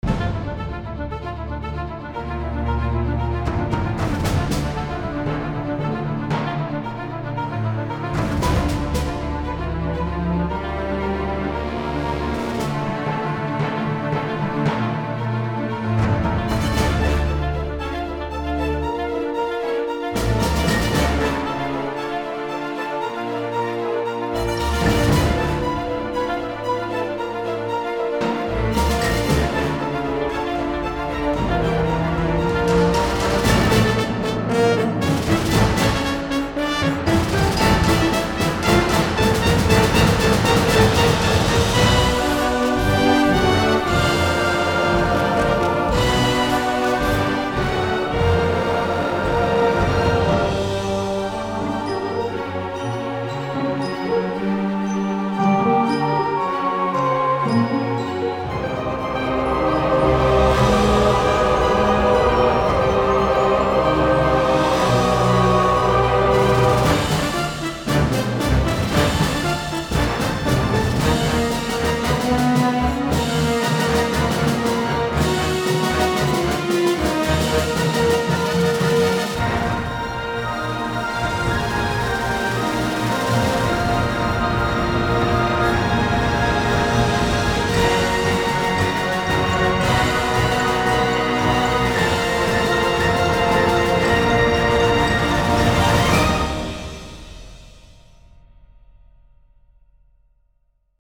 Orchestral, Chamber and Cinematic
ActionAdventure3.mp3